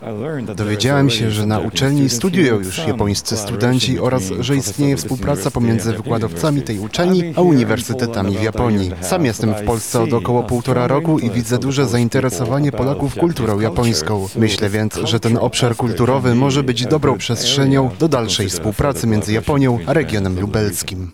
Tym razem w ramach kwietniowego spotkania do Lublina został zaproszony Akira Kono – Ambasador Nadzwyczajny i Pełnomocny Japonii w Polsce:
Ambasador-Akira-Kono_tlumaczenie.mp3